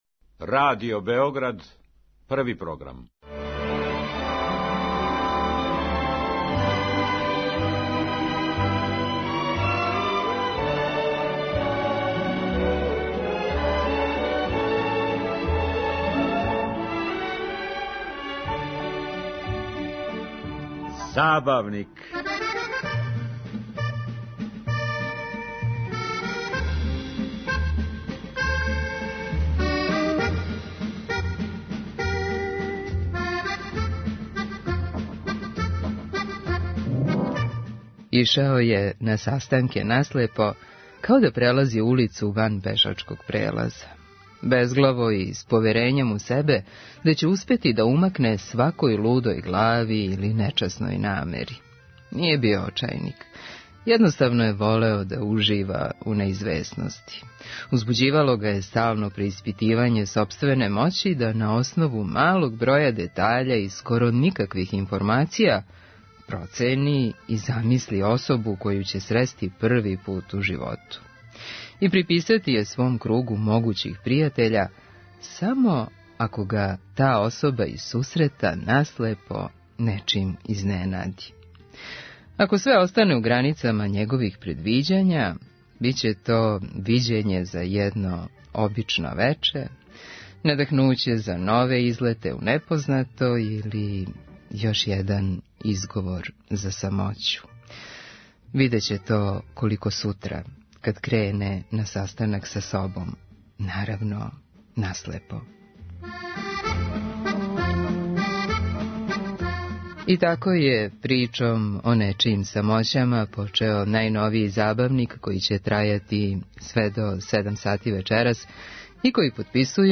мини евергрин интервју
евергрин музика